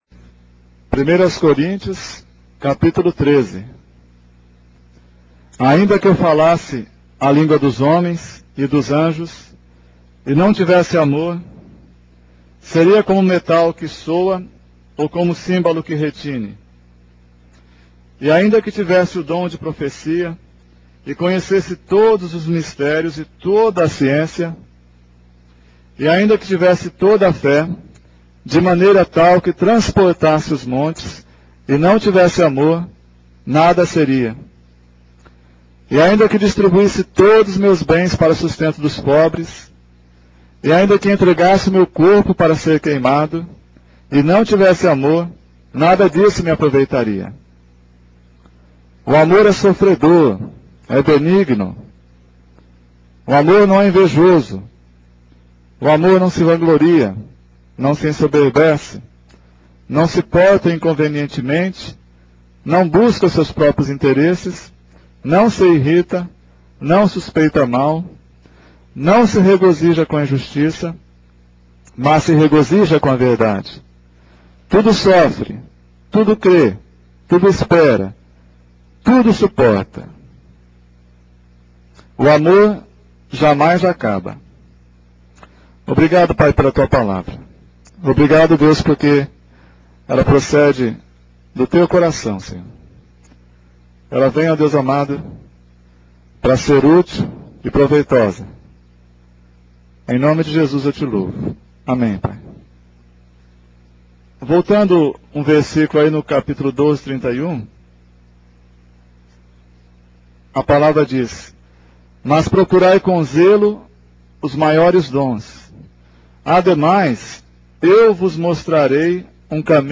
Estudo em Paranava� em 2005